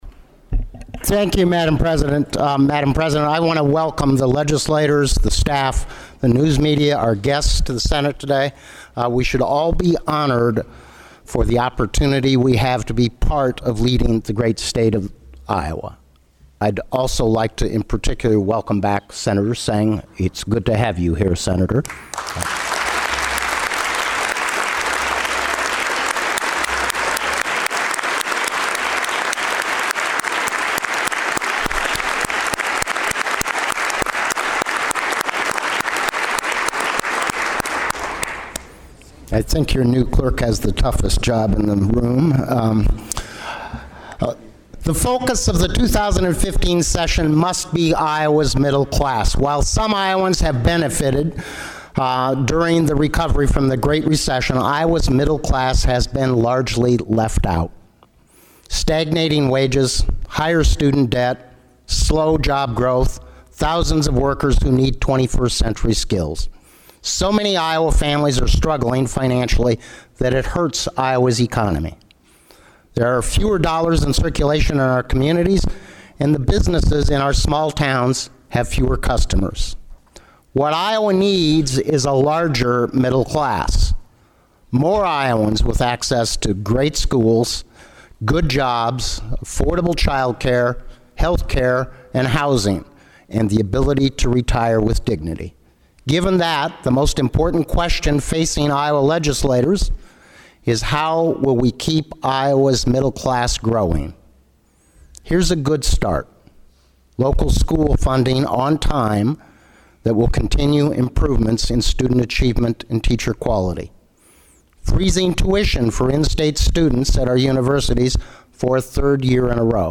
The top Democrat in the Iowa legislature used his opening day speech to challenge Republicans to boost state support of Iowa’s public schools.
AUDIO of Gronstal’s speech, 6:30